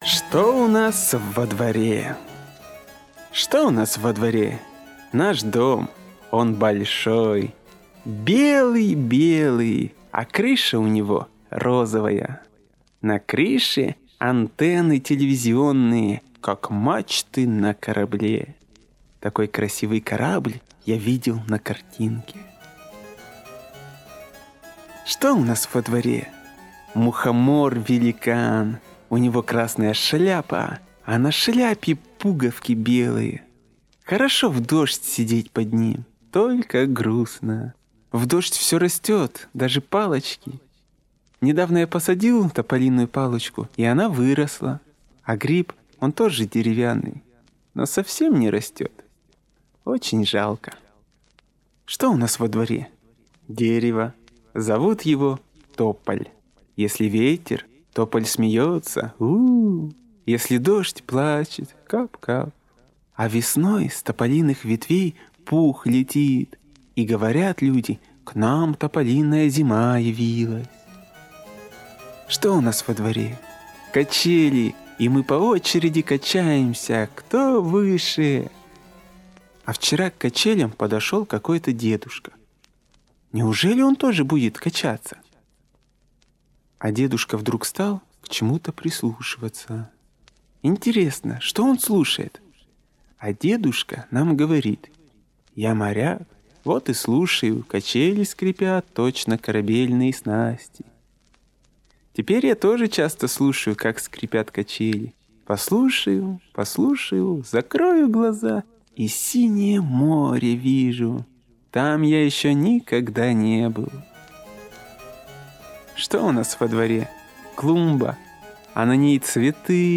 Аудиосказка «Что у нас во дворе»